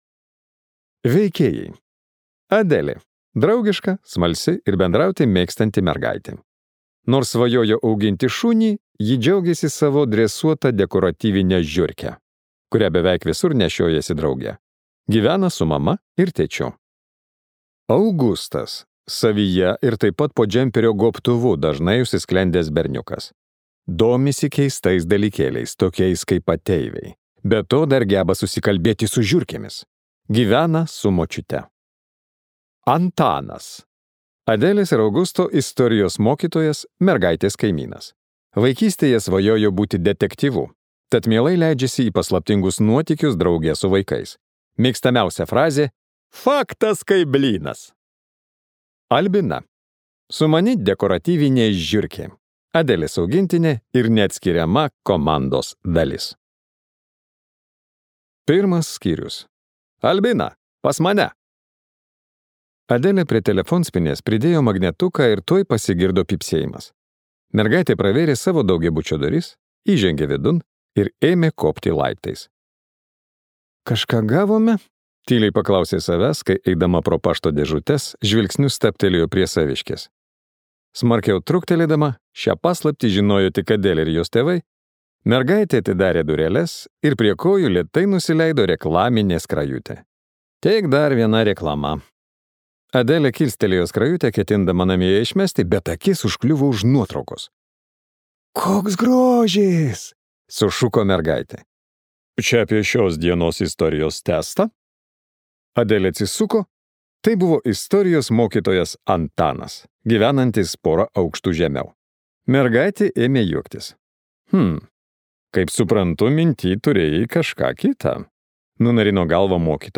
A komanda ir šuo už milijoną | Audioknygos | baltos lankos